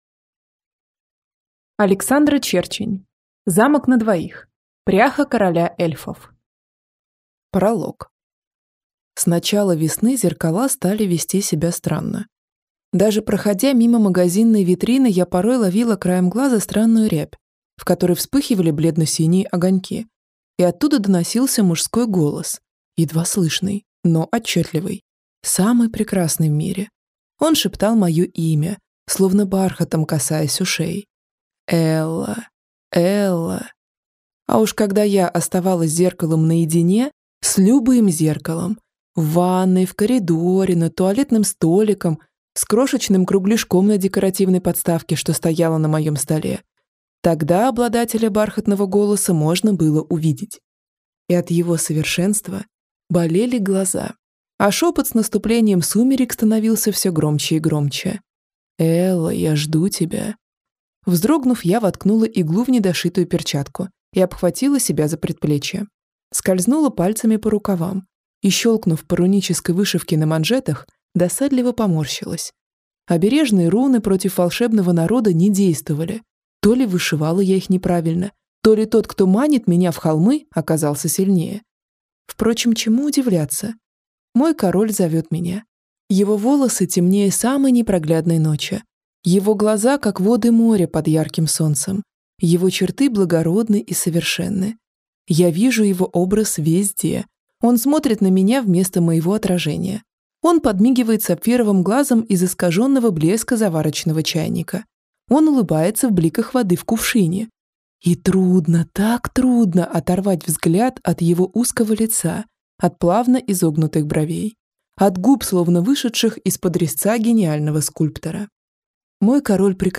Аудиокнига Замок на двоих. Пряха короля эльфов | Библиотека аудиокниг